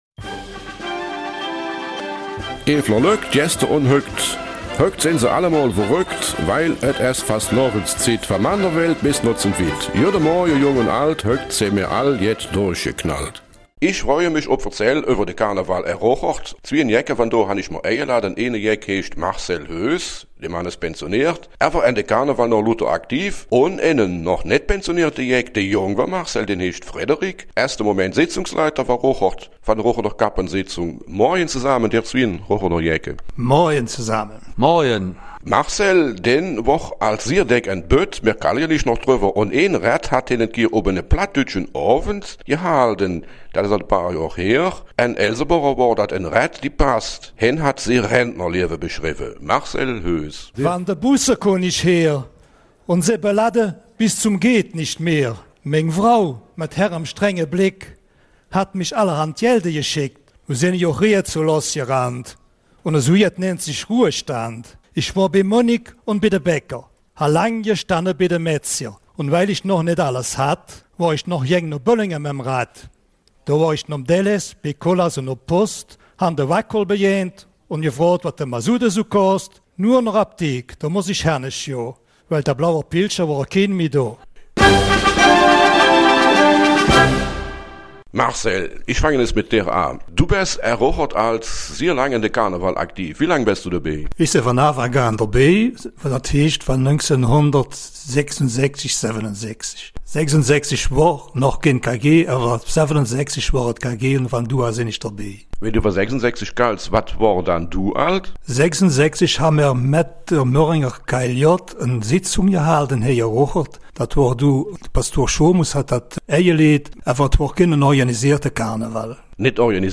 Eifeler Mundart: Karneval in Rocherath-Krinkelt